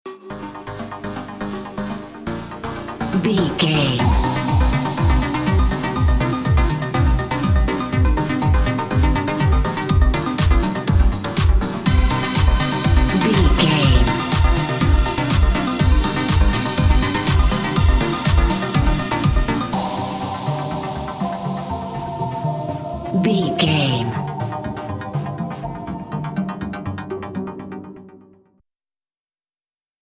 Aeolian/Minor
groovy
uplifting
futuristic
energetic
synthesiser
drum machine
house
techno
trance
synth leads
synth bass
upbeat